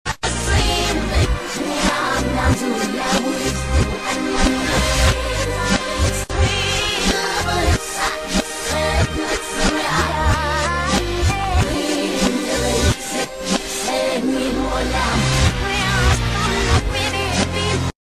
in reverse with creepy messages